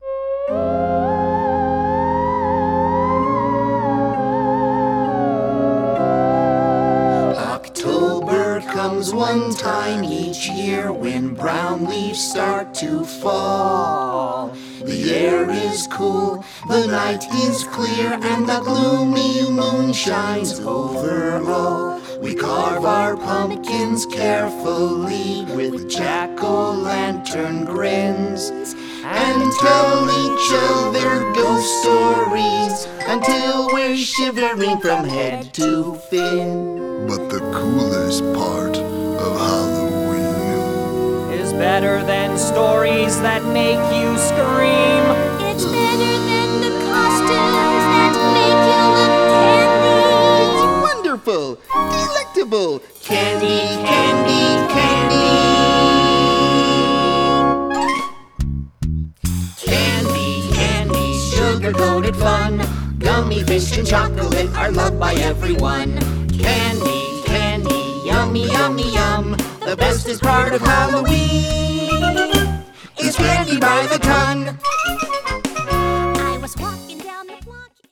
Children's Musical: